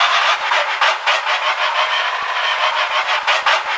rr3-assets/files/.depot/audio/sfx/transmission_whine/tw_offverylow.wav